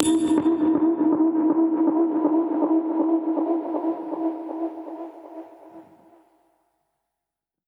Index of /musicradar/dub-percussion-samples/125bpm
DPFX_PercHit_A_125-06.wav